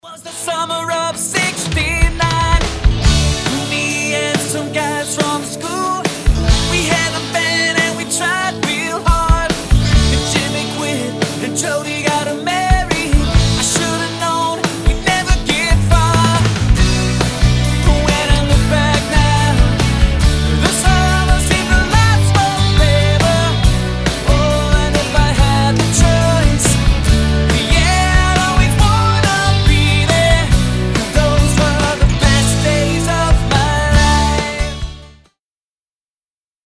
traditional sing-along-anthems